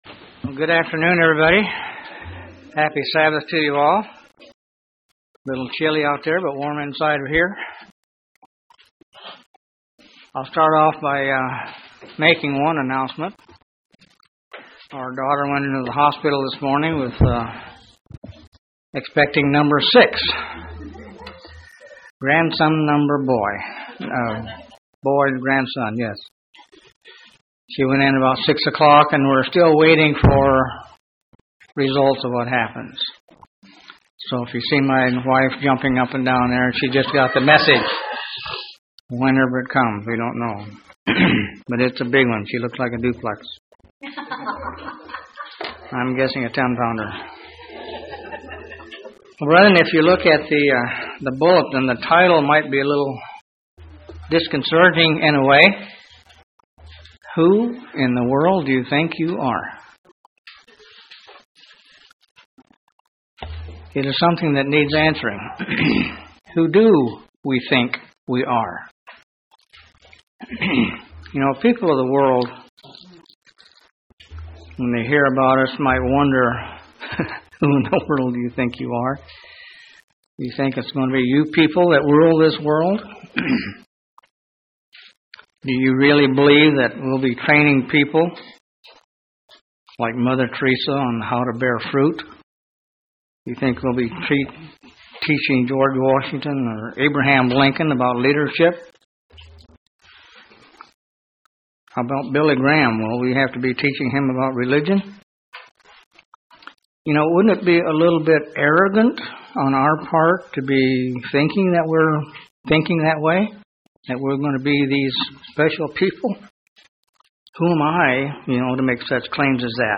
Given in Huntsville, AL
UCG Sermon Studying the bible?